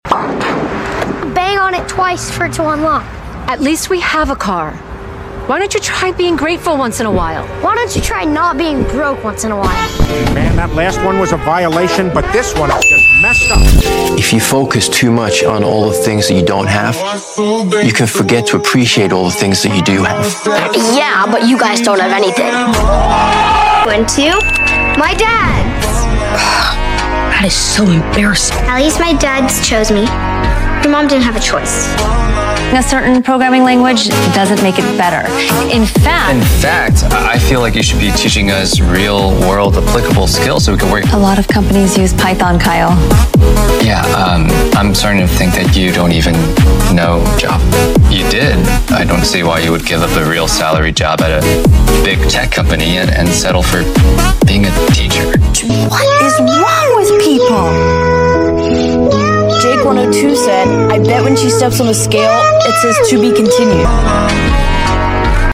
From hilarious comebacks to unexpected savage moments, we’re ranking the craziest and funniest Dhar Mann roasts ever! 😱🤣 These aren’t your typical ranking shorts — this one’s packed with funny edits, wild reactions, and entertaining commentary to keep you hooked!